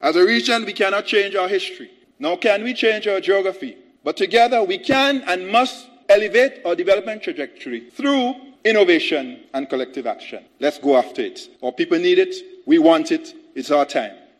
That was, Mr. Timothy Antoine, Governor of the Eastern Caribbean Central Bank at the unveiling of an ambitious 2026–2031 strategic plan, calling for urgent, coordinated action to transform the region’s economic future. The ceremony took place at the Headquarters’ in Basseterre, St. Kitts, on March 31st, 2026.